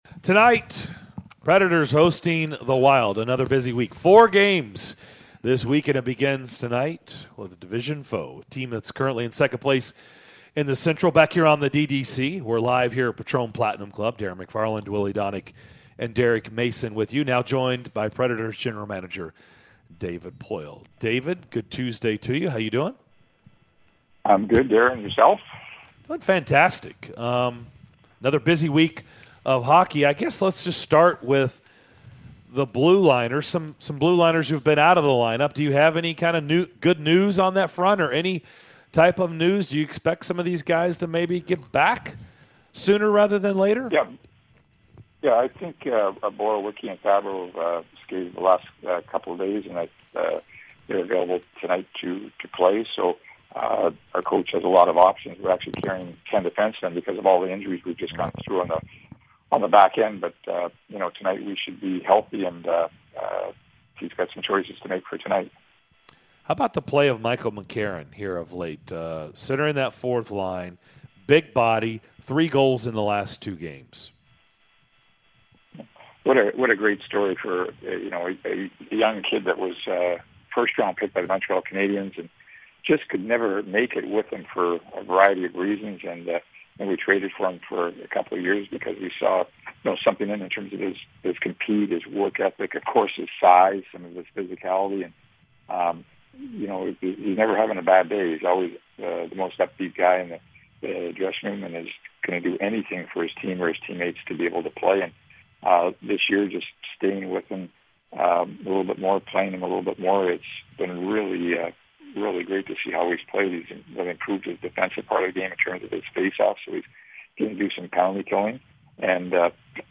Nashville Predators General Manager David Poile joined the show for his weekly visit to discuss the latest from the team and the push for the playoffs!